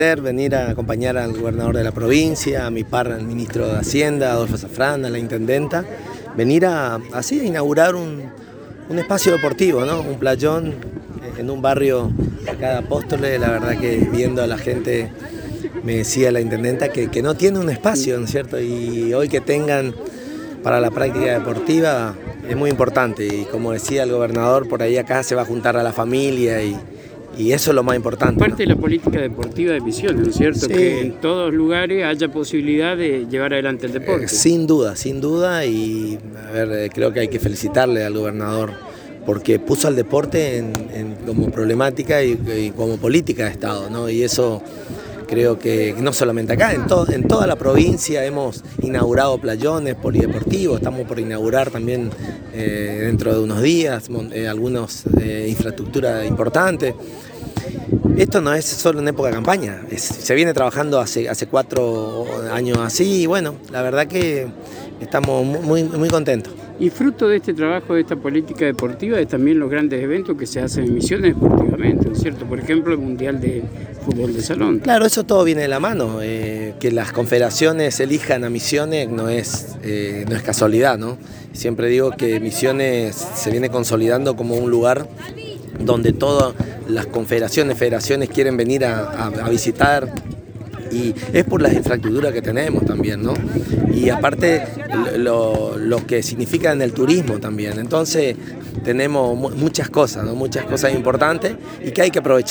El Ministro de Deportes de Misiones, Héctor Corti, visitó Apóstoles acompañando al gobernador en el acto inaugural del playón deportivo en el Barrio Itatí.